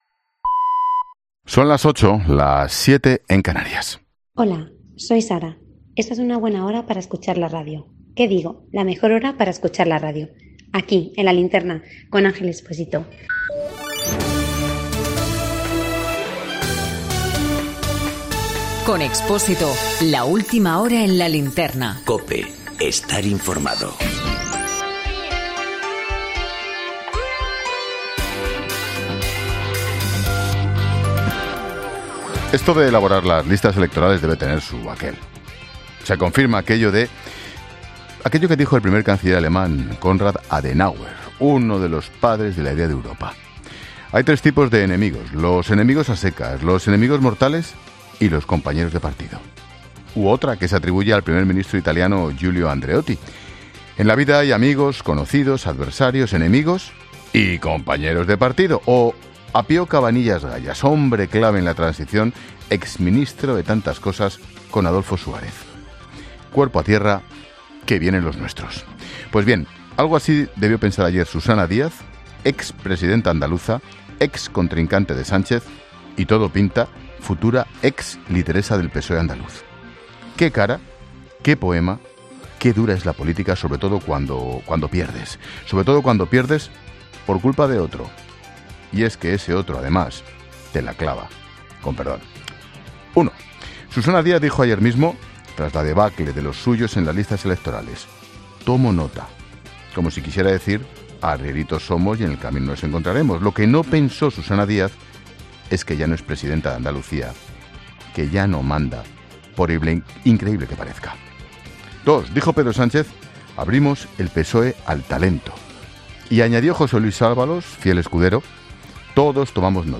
AUDIO: El análisis de la actualidad de este lunes con Ángel Expósito en 'La Linterna'